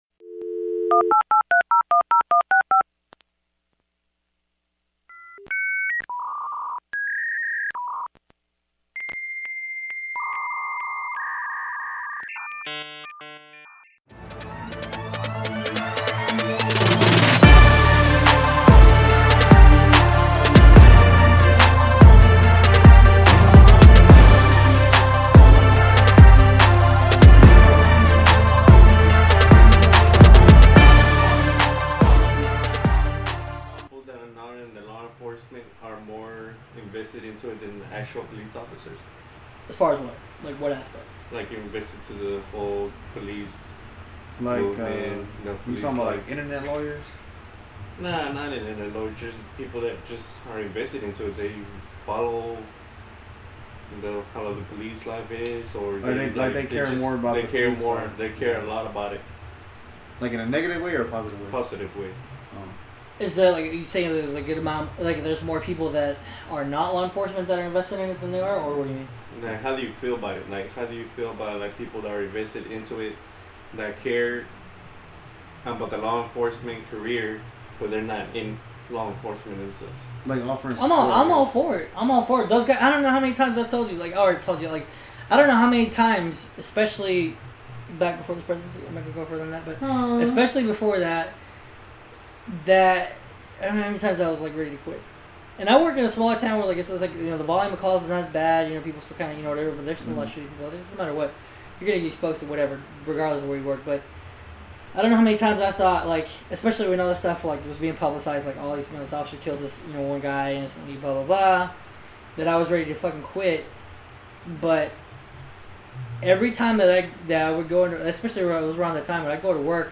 This is the second part of the interview